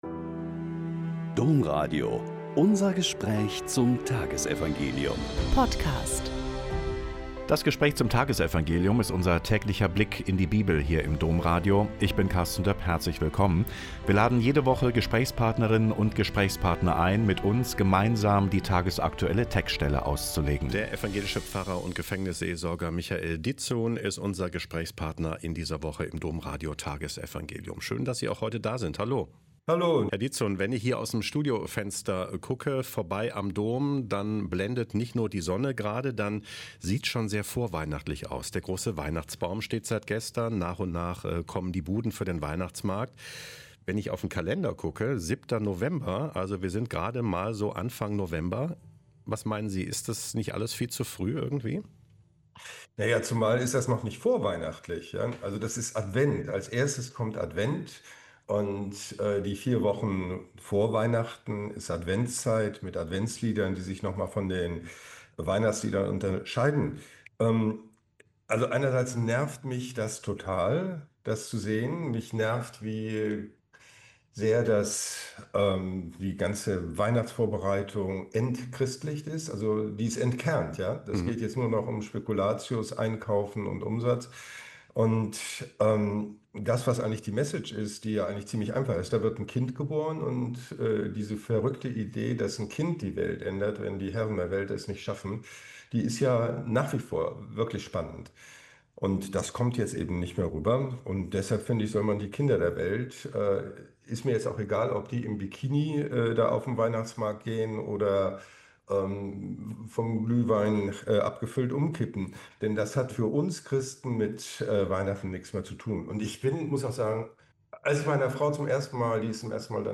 Lk 16,1-8 - Gespräch